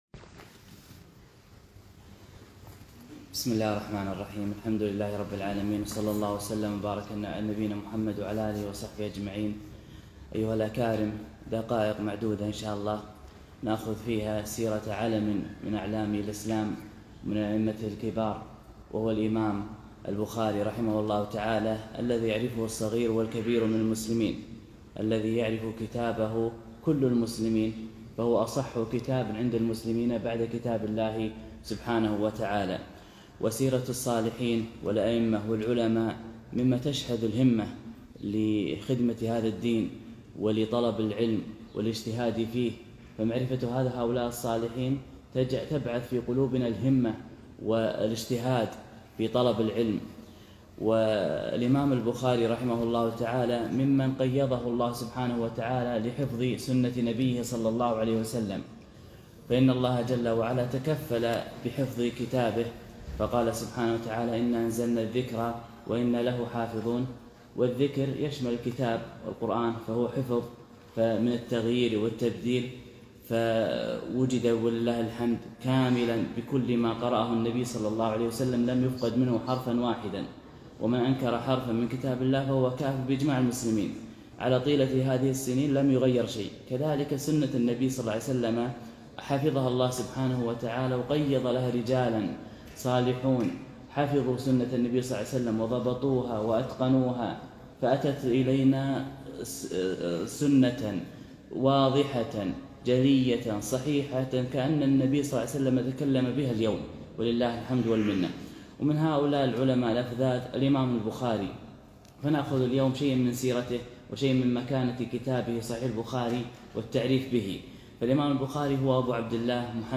محاضرة - سيرة الإمام البخاري رحمه الله تعالى.